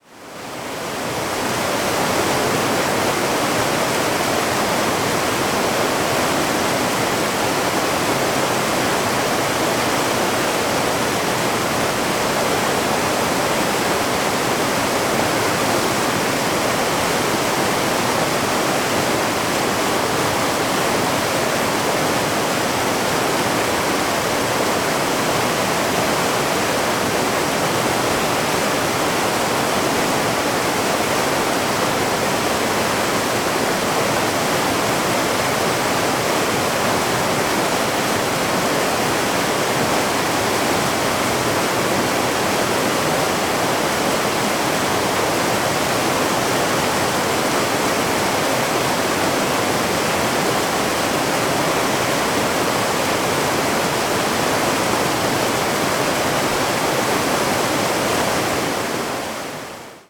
Spring trip to Yosemite Valley
Rushing of Tenaya Creek
002_tenaya.ogg